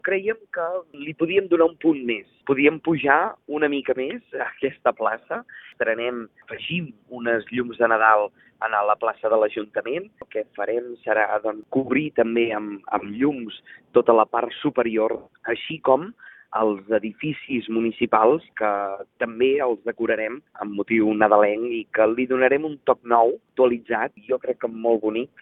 El tinent d’Alcaldia d’Activitat Cultural, Josep Grima, avança novetats en la decoració del punt central de la plaça de l’Ajuntament. S’estan instal·lant tires de llum LED que connectaran l’edifici consistorial amb l’Ajuntament Vell.